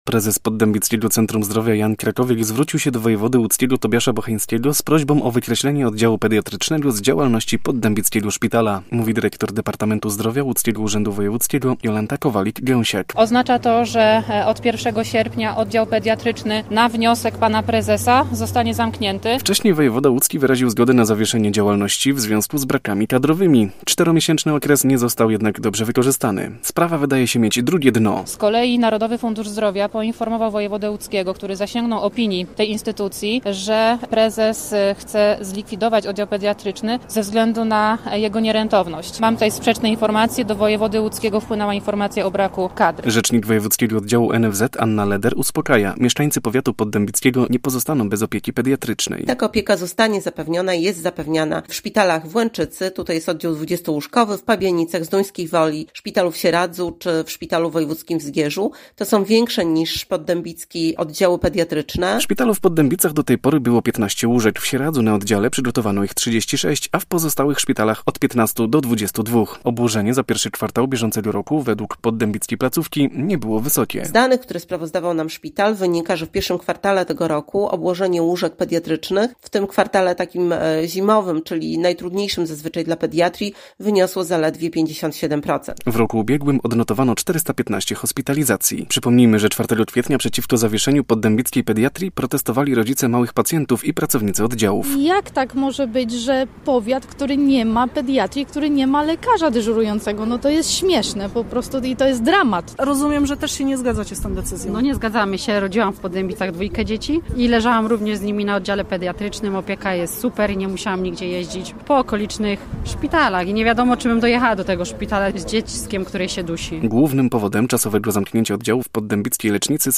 Posłuchaj relacji i dowiedz się więcej: Nazwa Plik Autor Oddział pediatryczny w Poddębicach zostanie zamknięty.